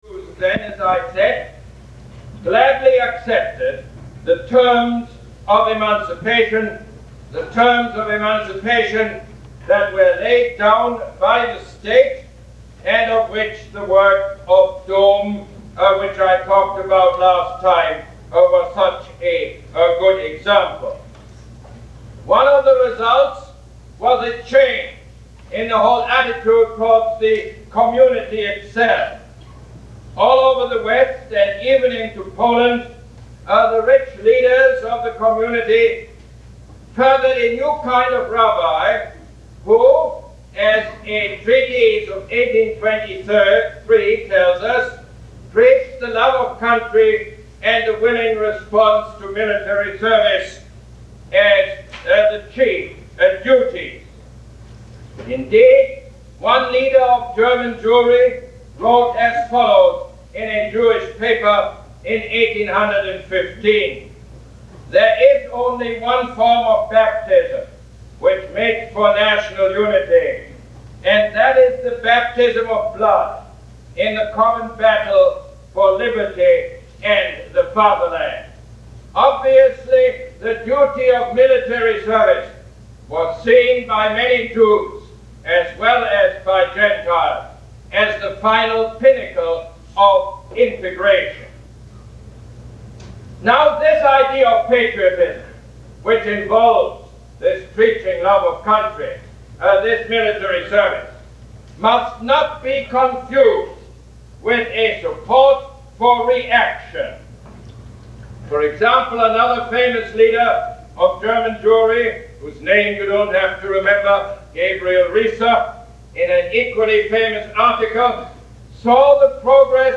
Lecture #3 - February 17, 1971